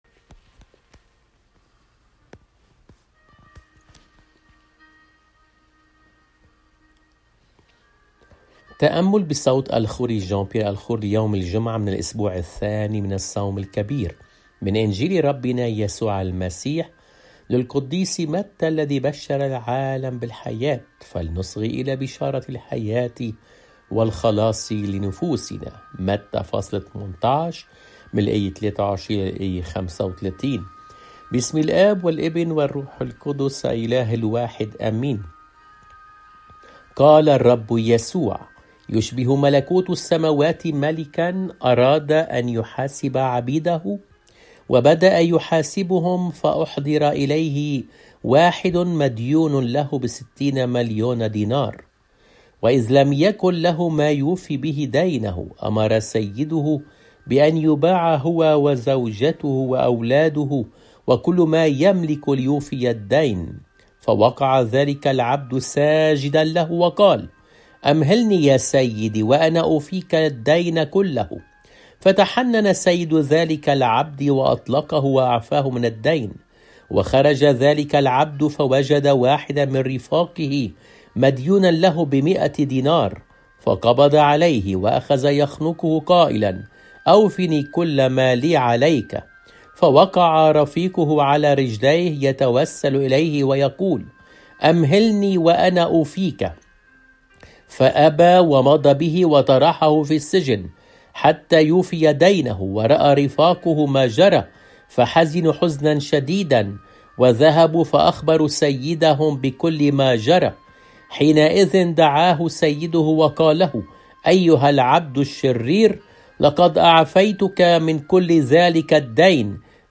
الإنجيل